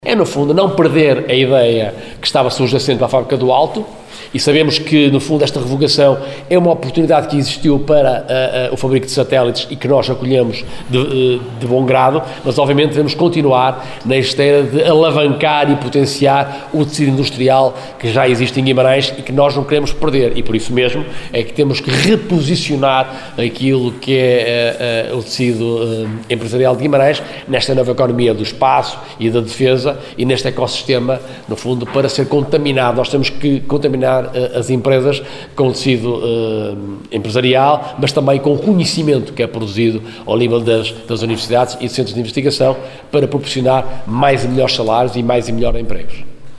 Declarações de Ricardo Costa, vereador do PS no executivo de Guimarães… falava esta segunda-feira em sede de reunião de câmara a propósito da cedência em regime de comodato da Fábrica do Alto para a Instalação de Unidades de Produção e Teste de Satélites Óticos.